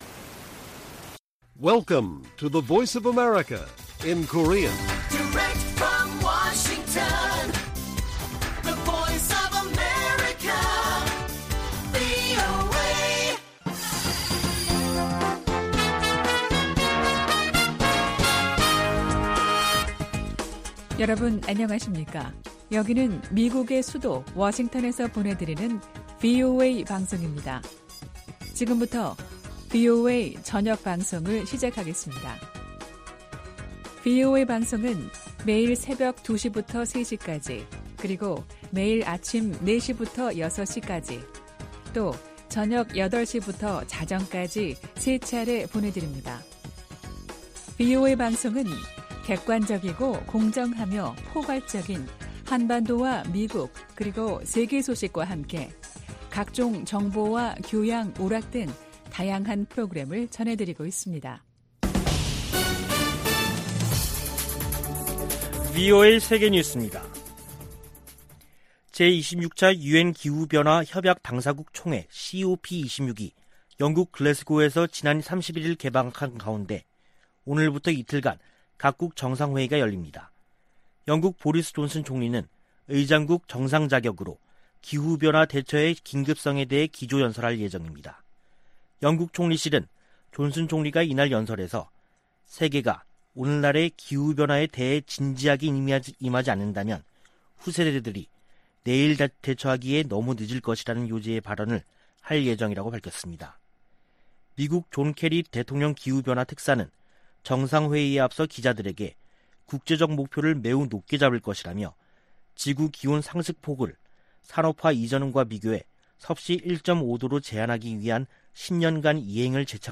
VOA 한국어 간판 뉴스 프로그램 '뉴스 투데이', 2021년 11월 1일 1부 방송입니다. 미 국무부가 북한의 대량살상무기(WMD) 확산 방지를 위해 활동하는 단체들에 총 1천200만 달러를 지원하기로 했습니다. 국경 봉쇄로 인한 북한의 식량난이 김정은 위원장의 지도력에 타격을 줄 수 있다고 전문가들이 분석했습니다. 미-중 갈등이 계속되는 가운데 북한과 중국이 전략적 협력을 강조하고 있습니다.